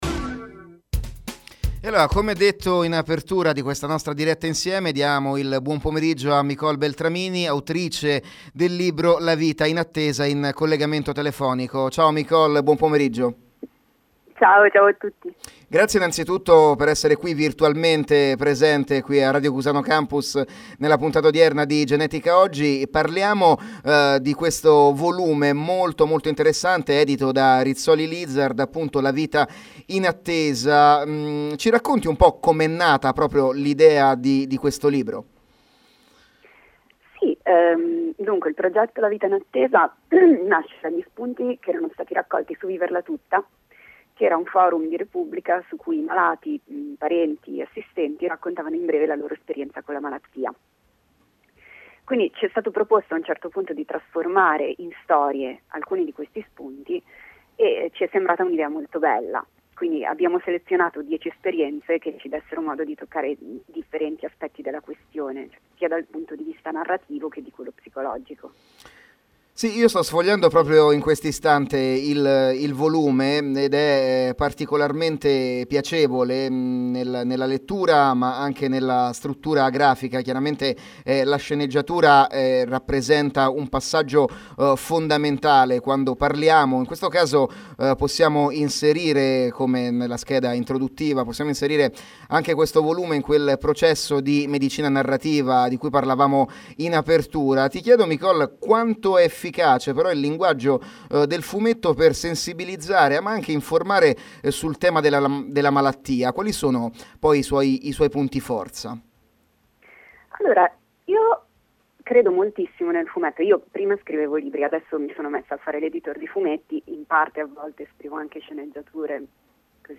Lo Spazio Bianco è lieto di presentarvi il podcast di Giochi a Fumetti, la trasmissione radiofonica in onda ogni sabato su Radio Cusano Campus.
Intervista